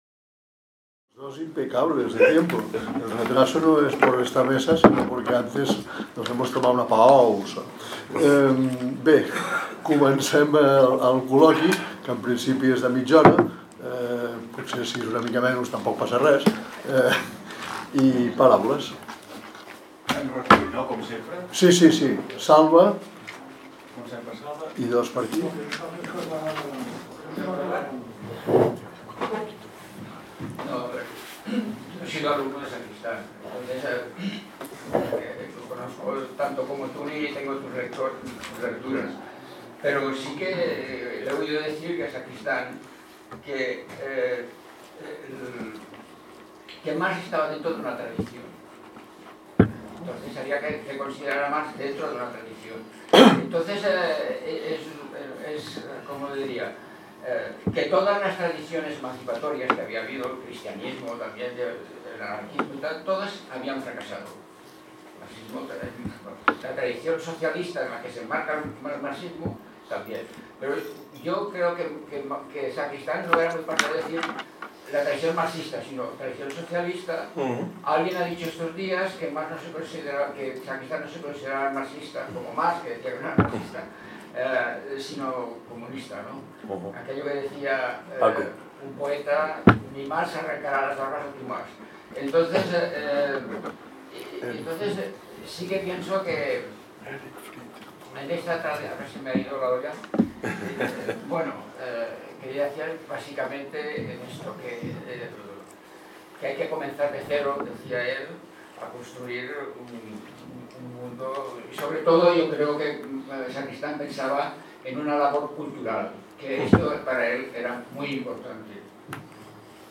Debat sessió 11
>  Onzè debat que s'emmarca dins el Simposi Trias 2025, organitzat per la Càtedra Ferrater Mora, en col·laboració amb el Memorial Democràtic i dedicat al filòsof Manuel Sacristán.